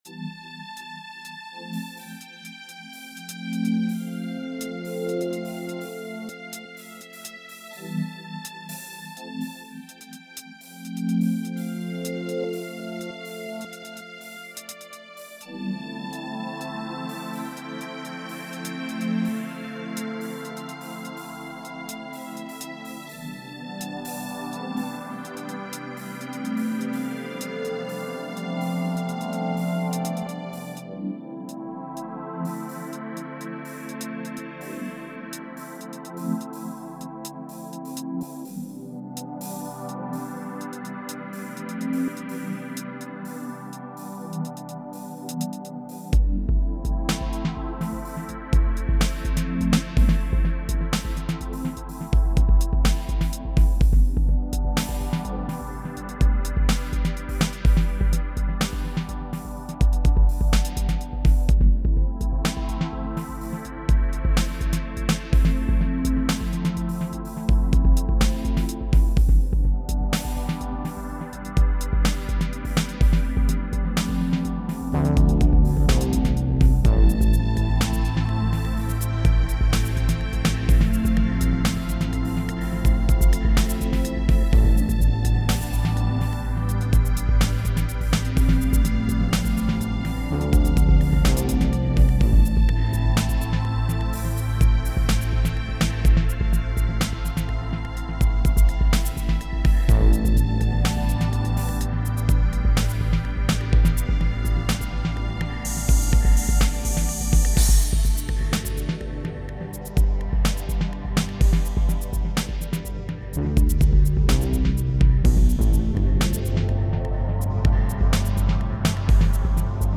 This is the story of my 2012, in the form of drums and strings.